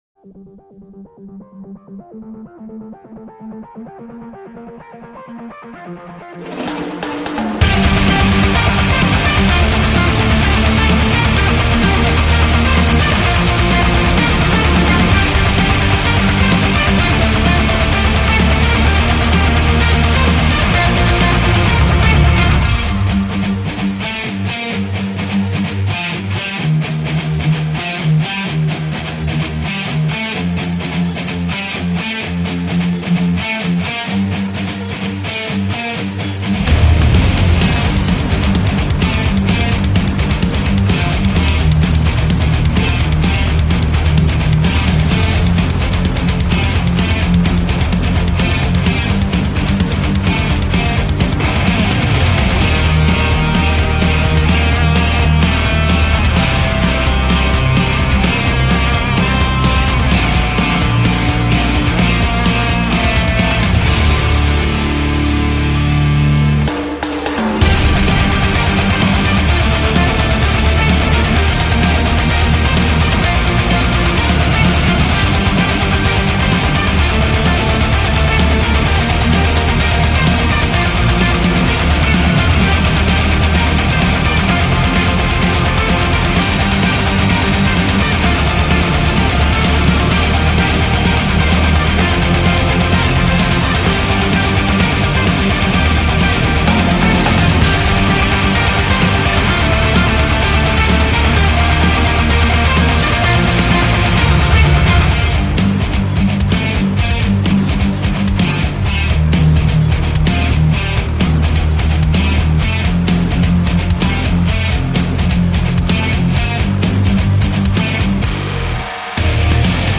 Жанр: Hard Rock